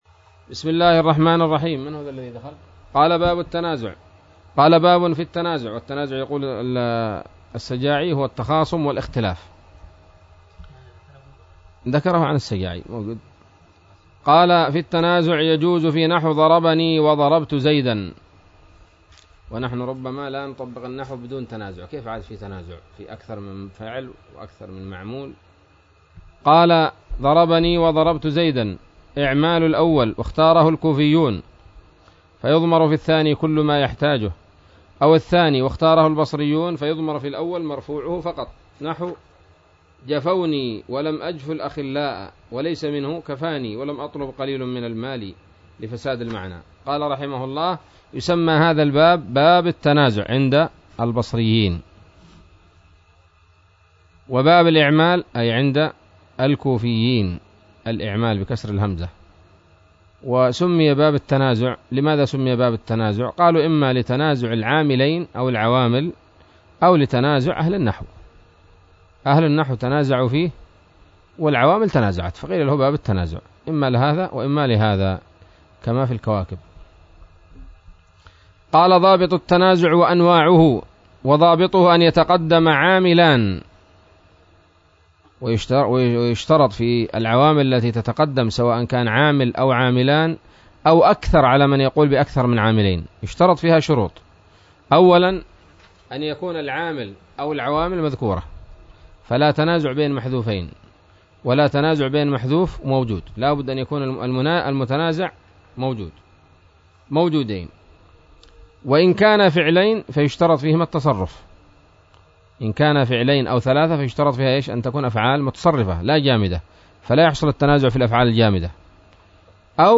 الدرس الحادي والثمانون من شرح قطر الندى وبل الصدى